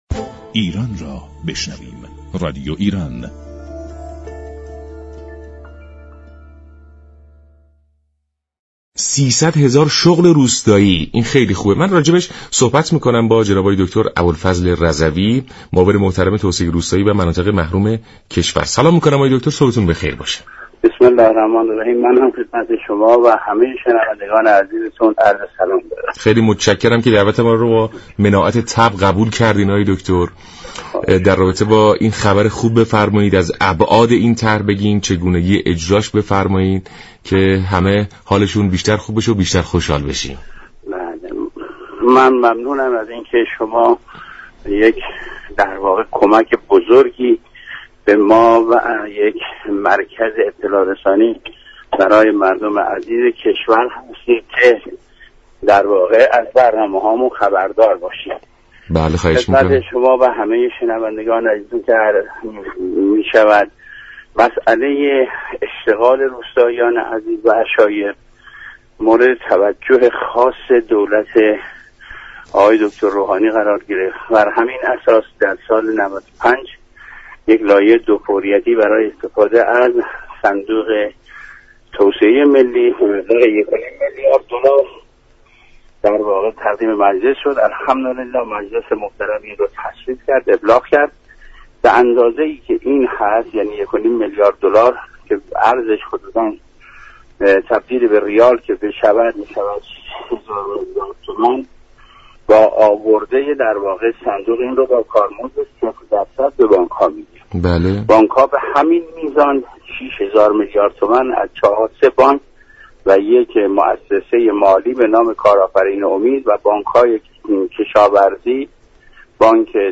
ابوالفضل رضوی معاون توسعه روستایی و مناطق محروم كشور در سلام ایران توضیح داد